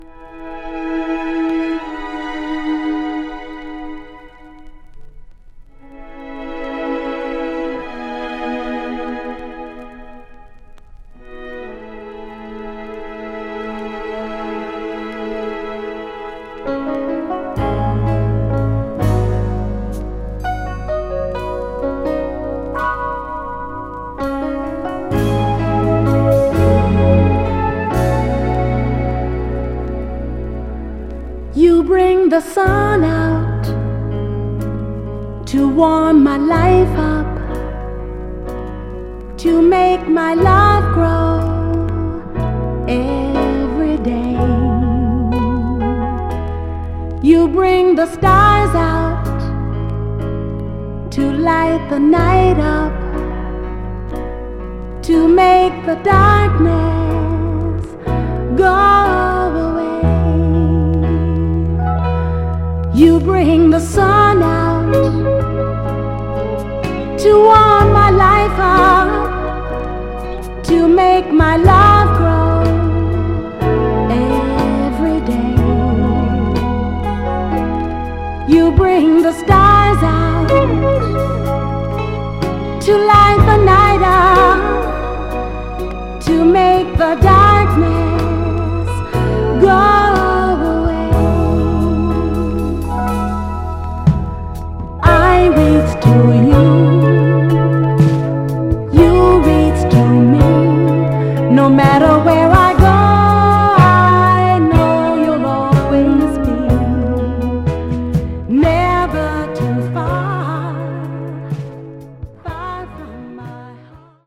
盤は全体的に細かい表面スレ、線スレありますが、音への影響は少なくプレイ概ね良好です。
※試聴音源は実際にお送りする商品から録音したものです※